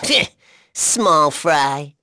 Neraxis-Vox_Victory.wav